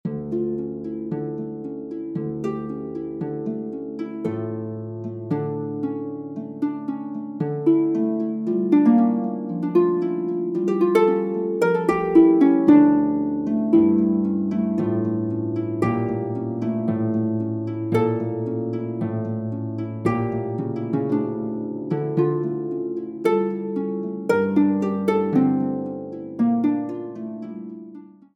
Music for an Imaginary Harp